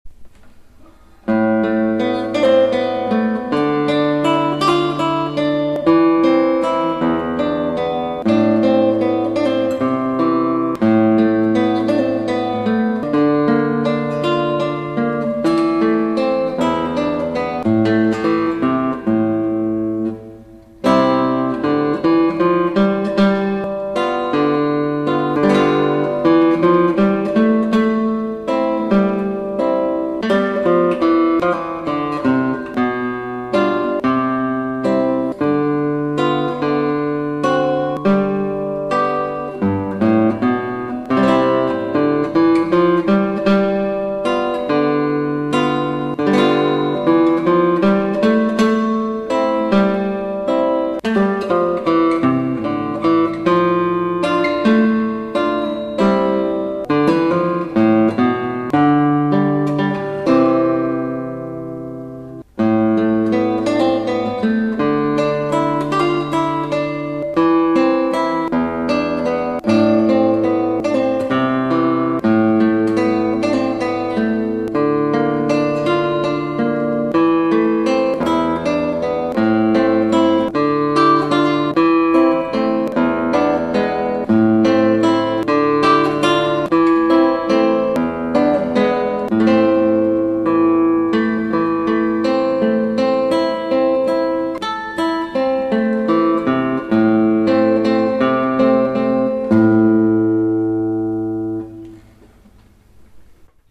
バラード、ヤイリギターで。